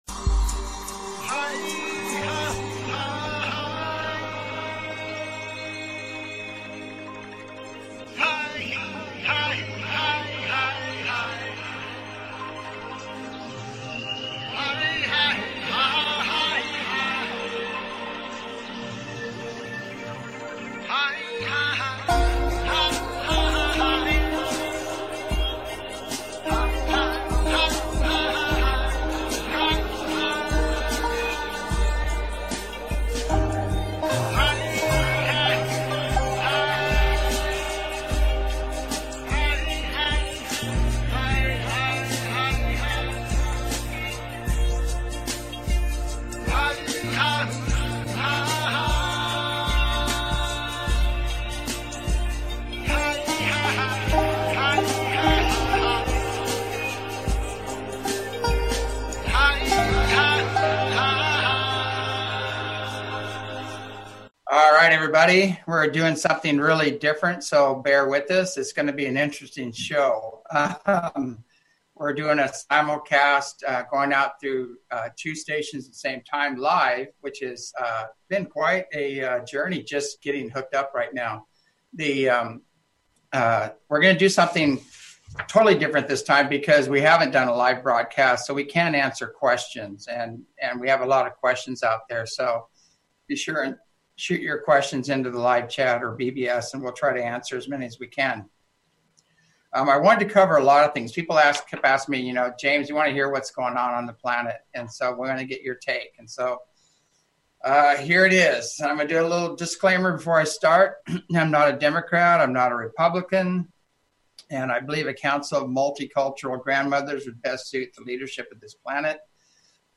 Talk Show Episode, Audio Podcast, As You Wish Talk Radio and A Live Stream Simulcast on , show guests , about Live Stream Simulcast, categorized as Health & Lifestyle,News,UFOs,Physics & Metaphysics,Politics & Government,Society and Culture,Spiritual,Technology,Theory & Conspiracy
A Live Stream Simulcast